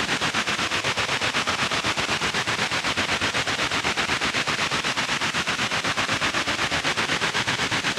Index of /musicradar/stereo-toolkit-samples/Tempo Loops/120bpm
STK_MovingNoiseE-120_01.wav